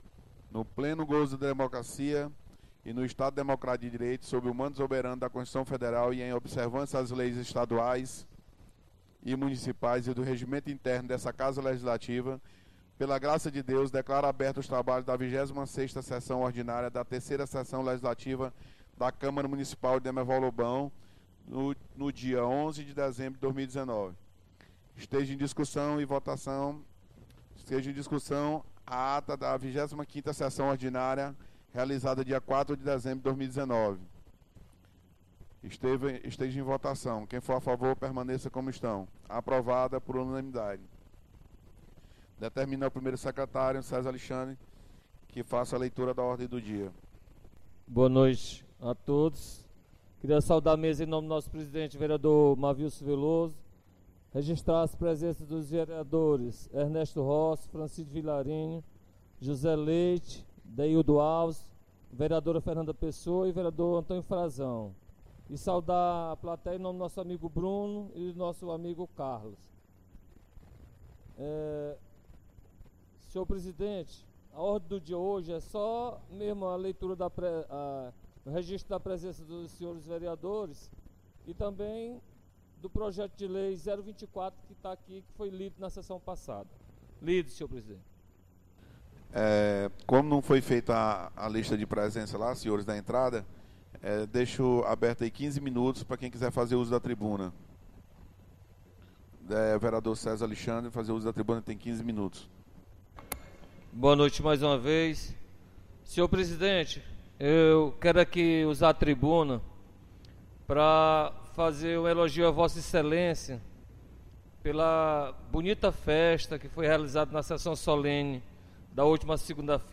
26ª Sessão Ordinária 11 de Dezembro